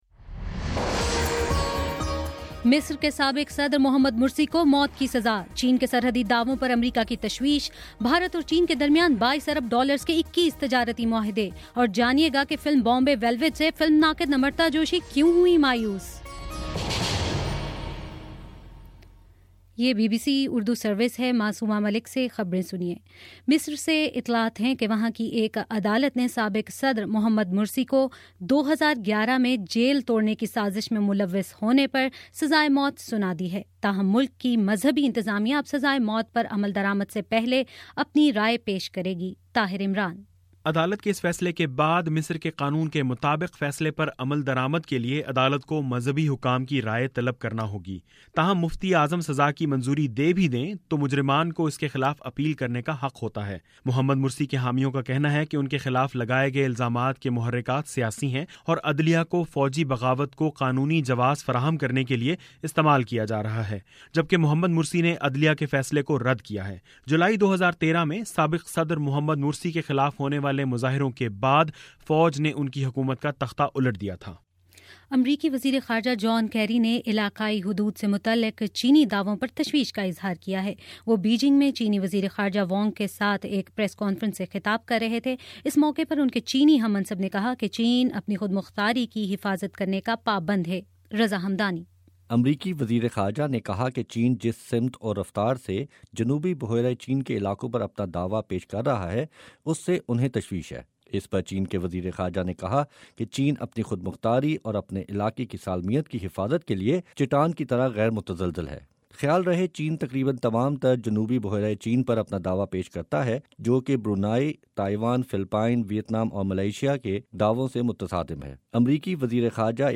مئی 16: شام پانچ بجے کا نیوز بُلیٹن